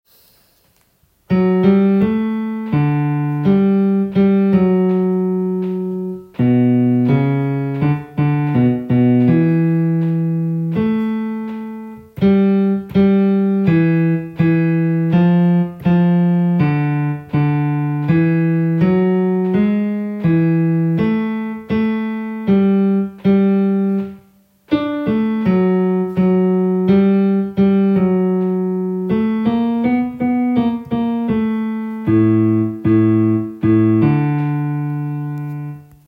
Kun koret